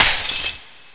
home *** CD-ROM | disk | FTP | other *** search / Amiga Format 29 / af029b.adf / Samples / GlassCrash ( .mp3 ) < prev next > Amiga 8-bit Sampled Voice | 1991-04-30 | 16KB | 1 channel | 16,726 sample rate | 1 second
GlassCrash.mp3